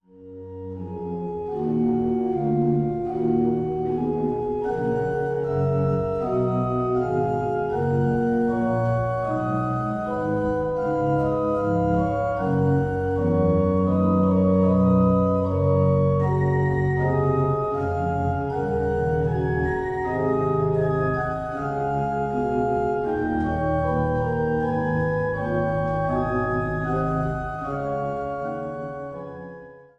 Großengottern, Waltershausen, Altenburg, Eisenach